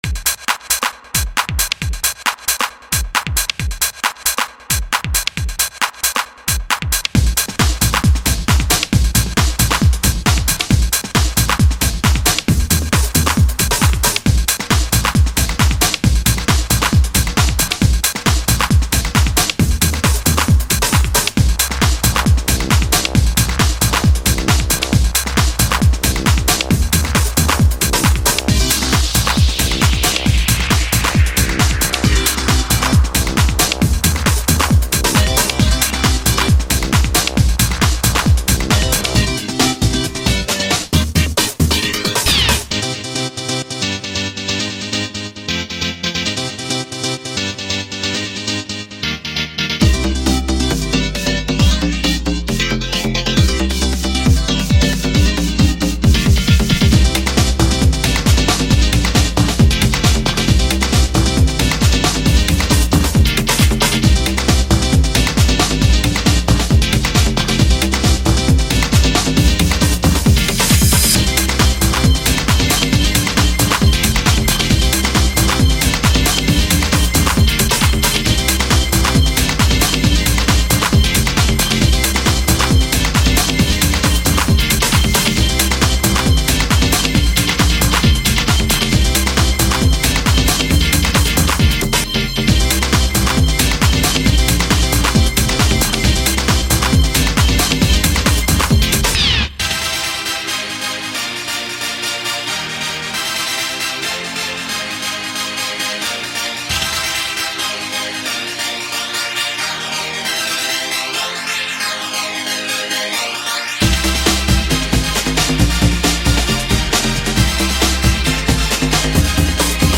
Ravehouse Bootleg Remix